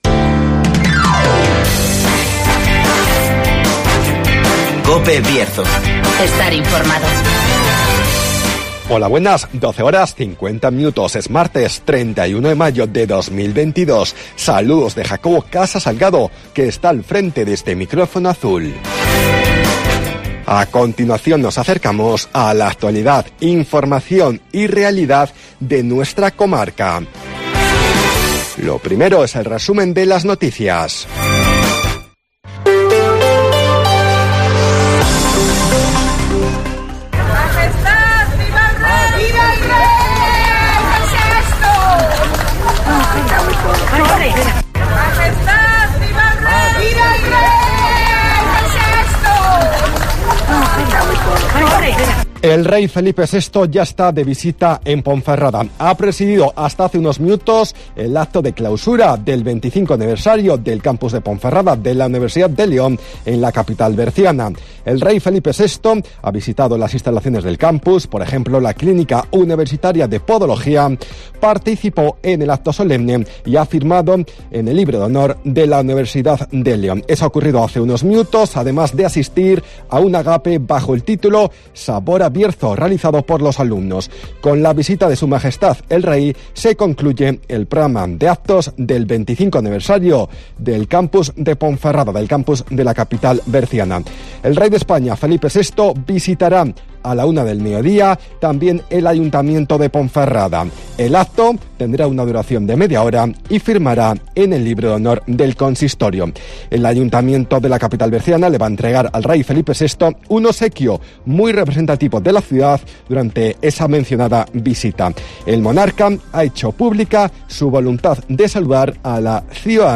AUDIO: Resumen de las noticias, El Tiempo y Agenda